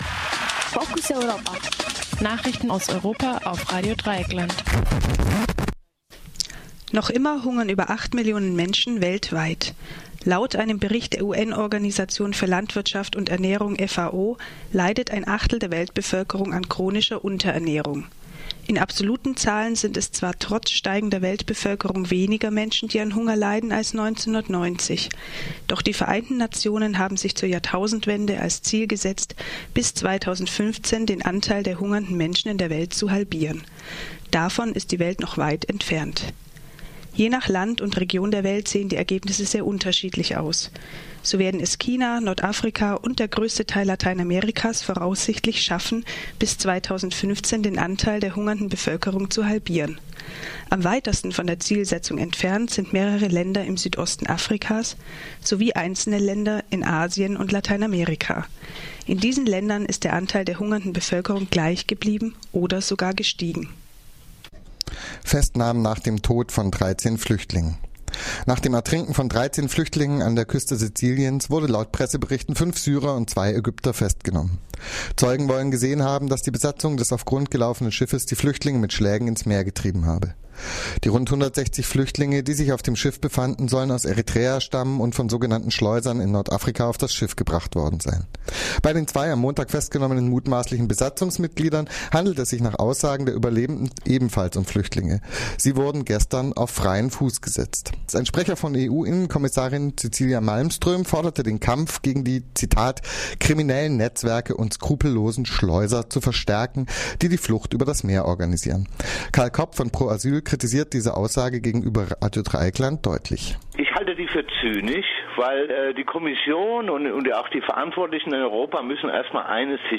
Focus Europa Nachrichten vom Mittwoch, den 02. Oktober - 12.30 uhr